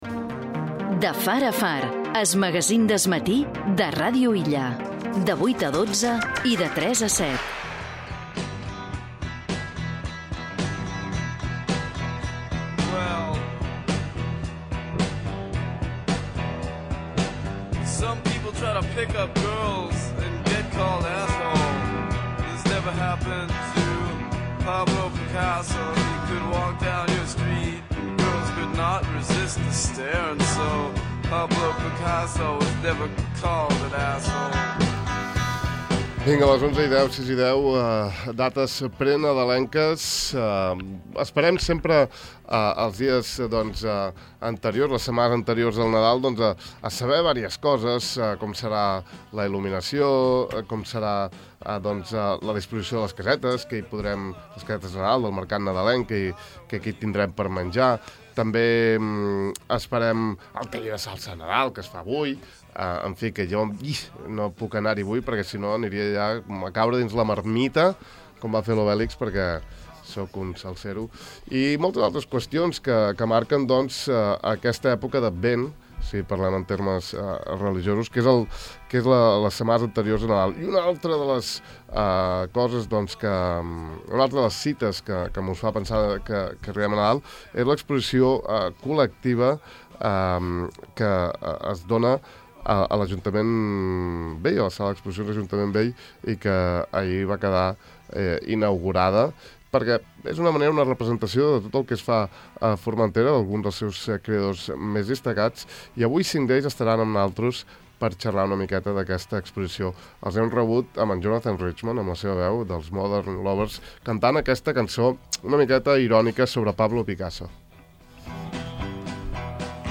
Aquí podeu escoltar la taula rodona d’ahir: